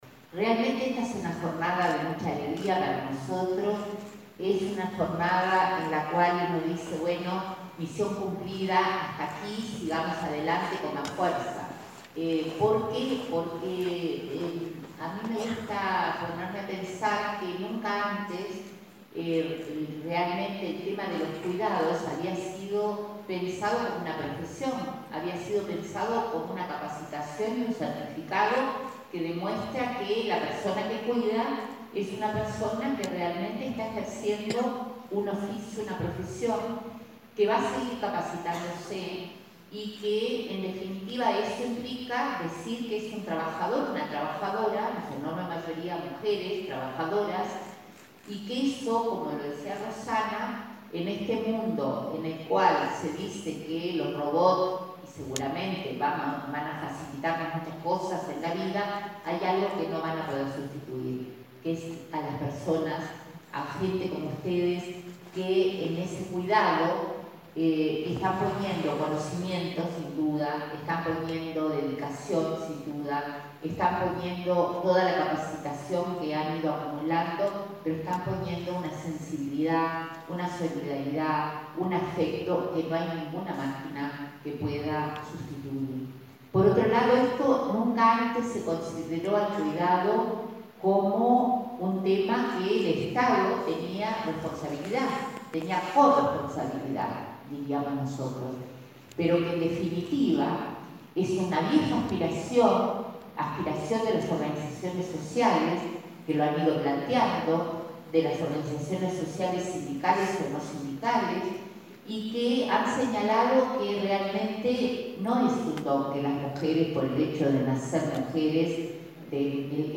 La titular del Mides, Marina Arismendi, subrayó este martes durante la entrega de 300 certificados de asistentes personales en Montevideo, que nunca antes el cuidado se había considerado un oficio o una profesión con la posibilidad de seguir capacitándose. Agregó que fue planteado al Consejo Consultivo de Cuidados que estos trabajadores puedan conformar un subgrupo en la negociación colectiva de los Consejos de Salarios.